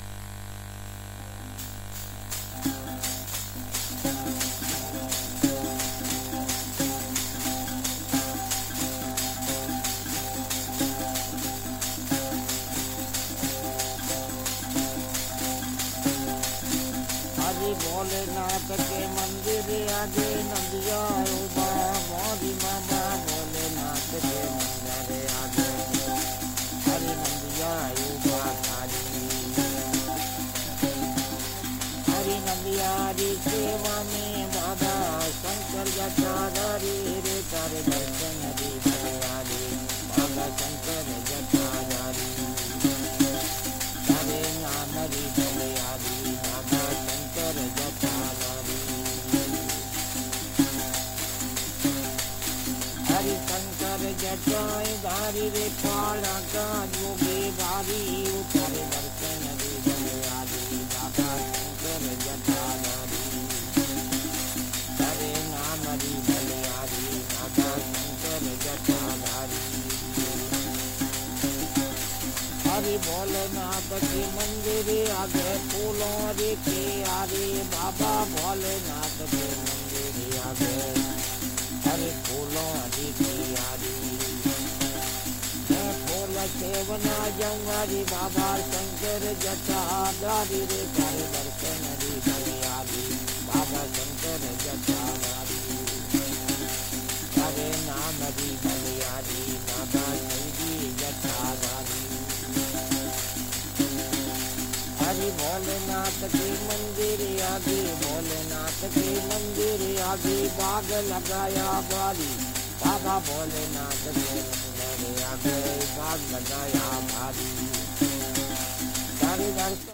ロマ（ジプシー）の起源でもあるラジャスタン州のストリート・ミュージックを収録した音楽集！
※レコードの試聴はノイズが入ります。